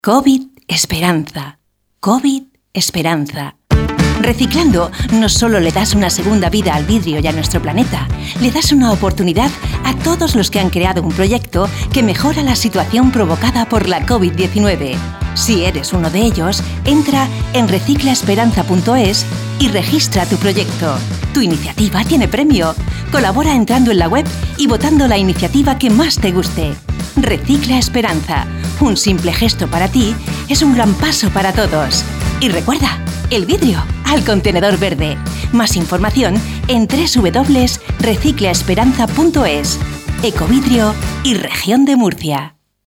CUÑA-RECICLA-ESPERANZA.mp3